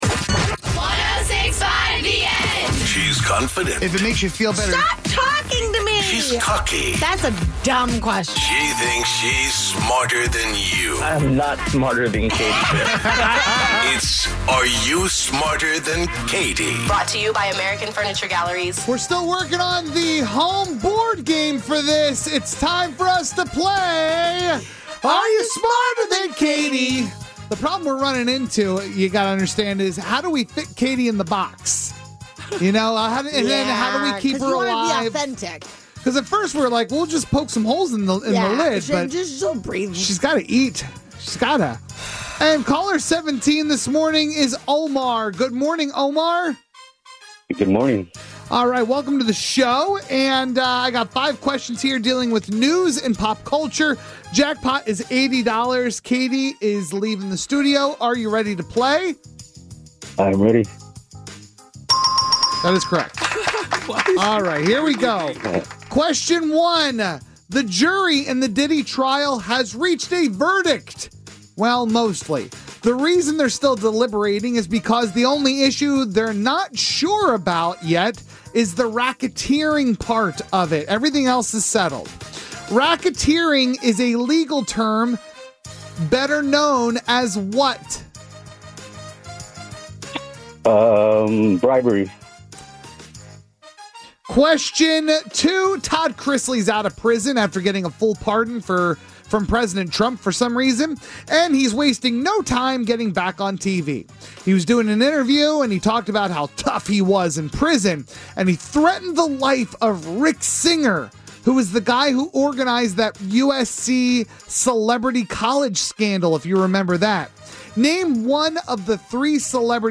A listener gets asked 5 questions